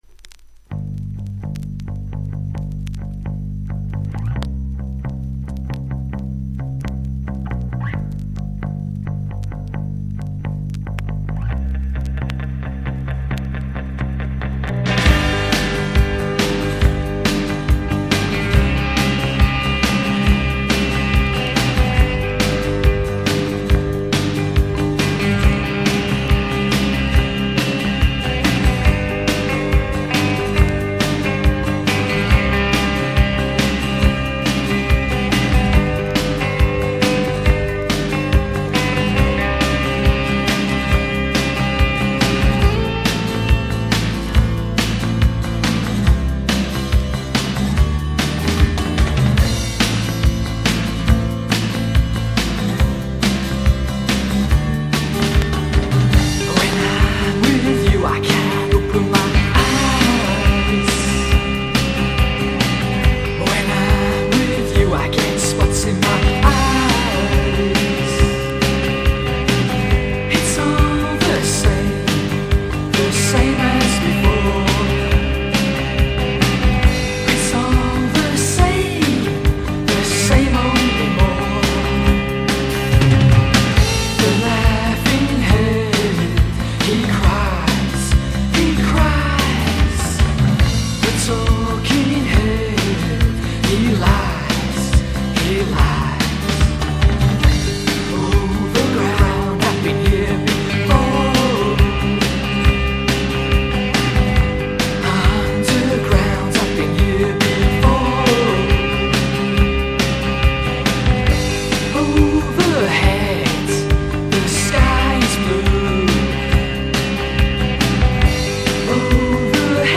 切なく聴かせるタイトル曲。